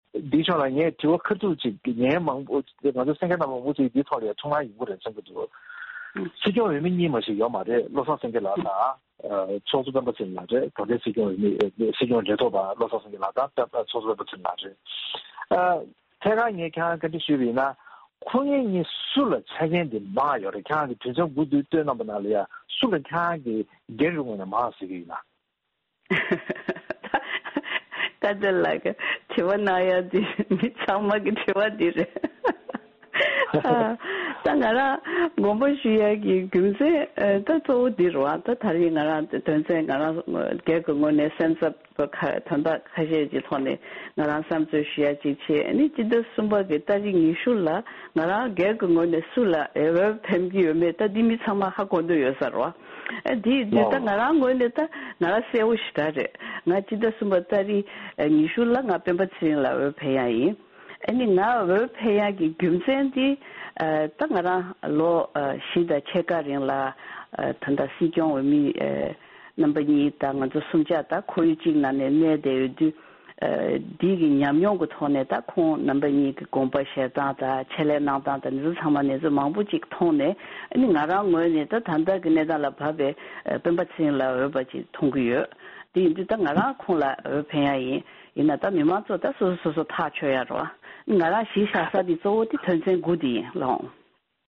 ༄༅།    །ཐེངས་འདིའི་གནད་དོན་གླེང་མོལ་གྱི་ལེ་ཚན་ནང་། བཀའ་ཟུར་བདེ་སྐྱིད་ཆོས་དབྱངས་མཆོག་གིས་སྲིད་སྐྱོང་འོས་མི་སྤྱི་འཐུས་ཚོཊ་གཙོ་སྤེན་པ་ཚེ་རིང་མཆོག་ལ་འོས་ཤོག་བླུཊ་གནང་རྒྱུ་ཡིན་པ་དང་། ཁོང་ལ་སྲིད་སྐྱོང་གི་མི་གཞིའི་འོས་ཆོས་ཚང་བས་རྒྱབ་སྐྱོར་གནང་བ་ཡིན་པ་དང་། དེ་བཞིན་བཀའ་བློན་ནས་དགོངས་པ་ཞུས་དགོས་པའི་རྒྱུ་མཚན་དང་དམིགས་ཡུལ་སོགས་ཀྱི་སྐོར་དམིགས་བསལ་བཅར་འདྲི་ཞུས་པའི་ལེ་ཚན་ཞིག